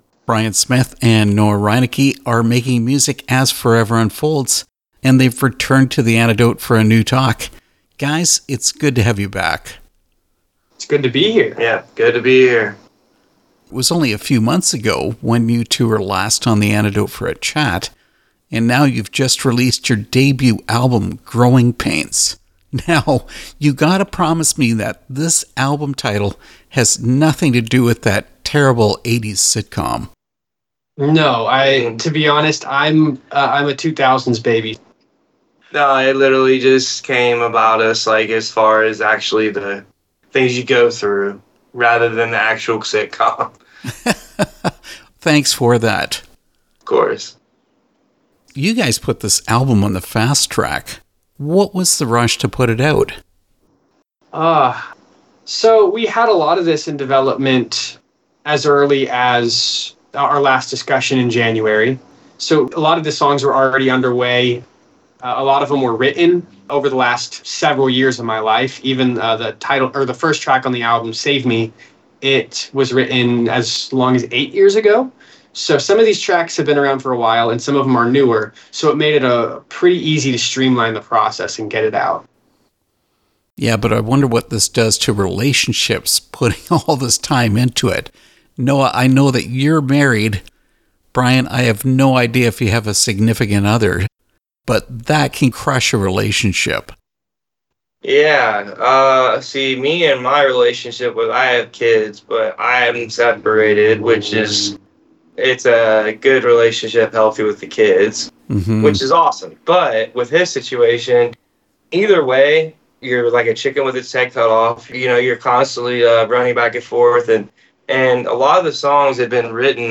Interview with Forever Unfolds – “Growing Pains”